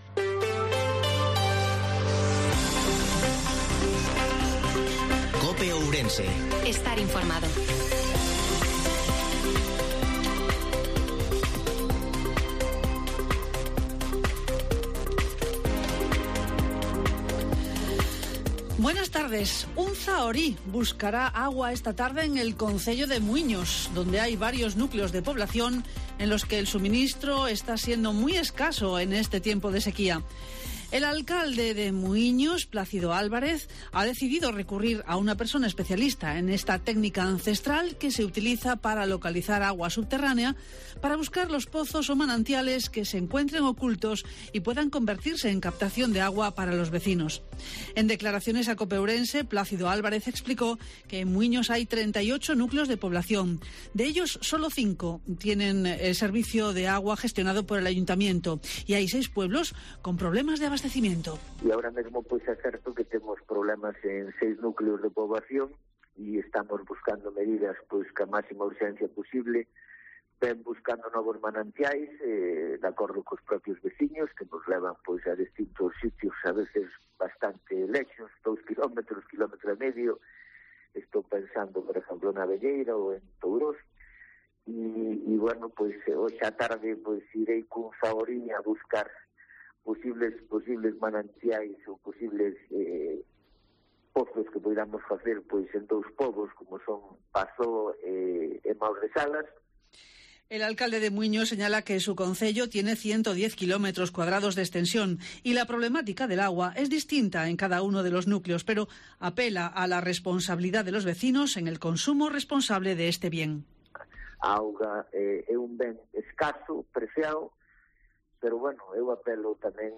INFORMATIVO MEDIODIA COPE OURENSE-23/08/2022